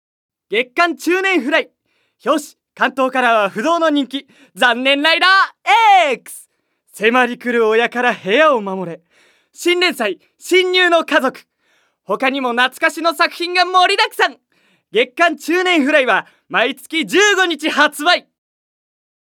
●ボイスサンプル４